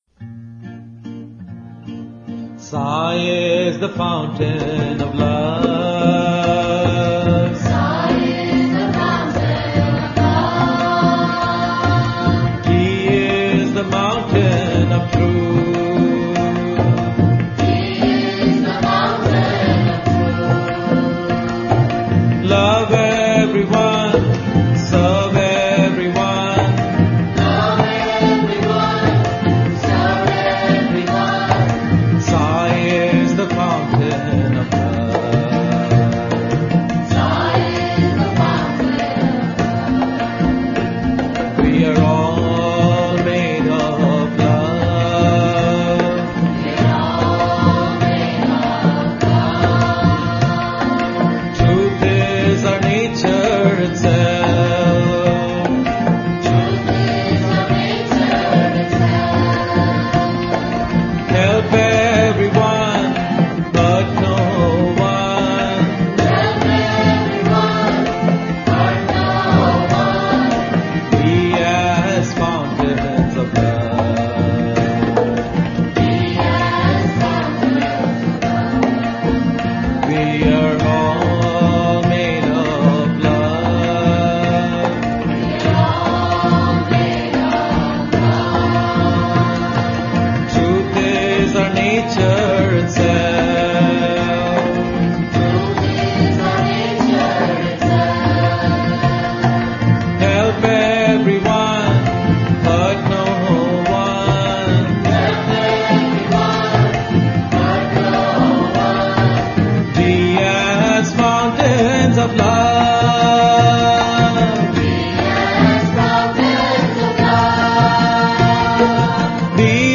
1. Devotional Songs
Major (Shankarabharanam / Bilawal)
6 Beat / Dadra
Medium Slow
3 Pancham / E
7 Pancham / B